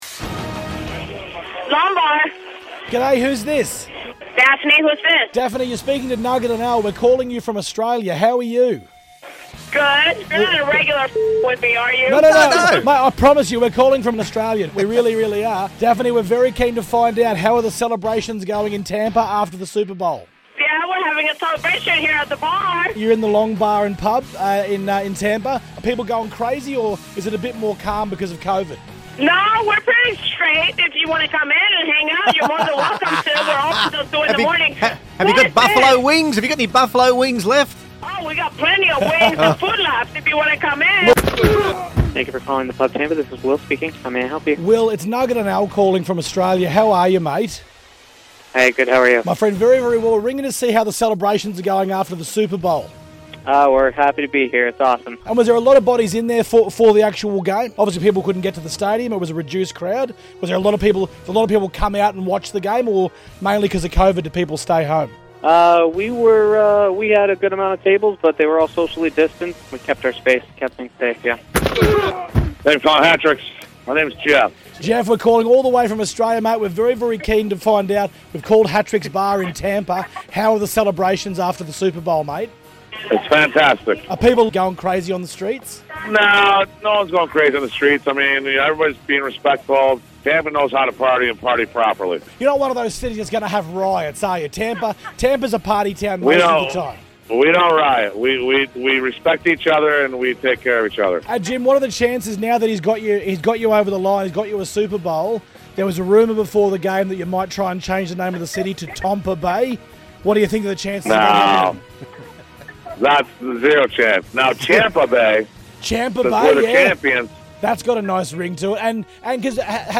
did a quick ring around today to see how Tampa is enjoying their Super Bowl victory..